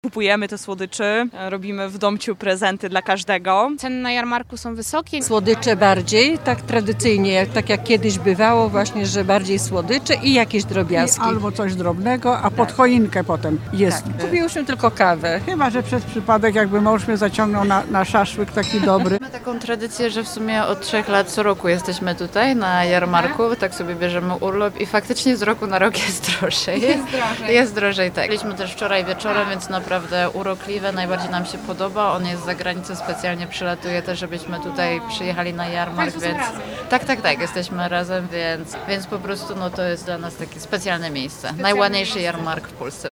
Zapytaliśmy turystów, co kupują i jak wrażenia.